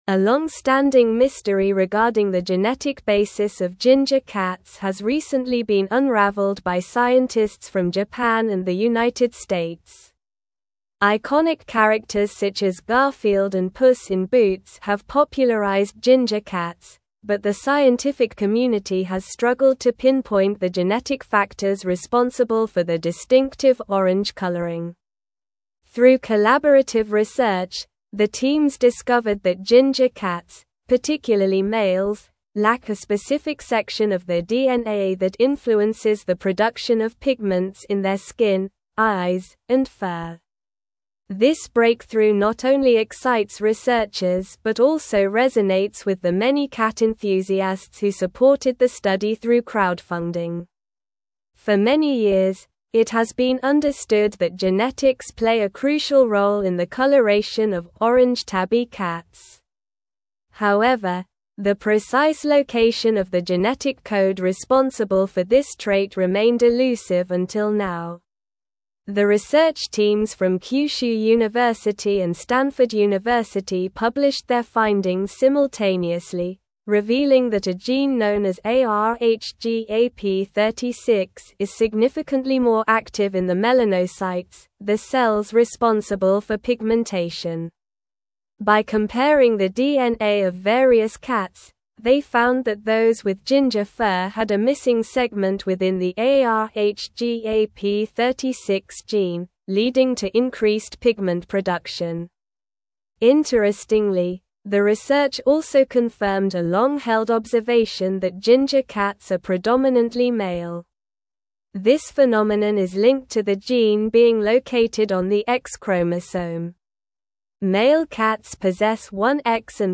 Slow
English-Newsroom-Advanced-SLOW-Reading-Genetic-Mystery-of-Ginger-Cats-Unveiled-by-Researchers.mp3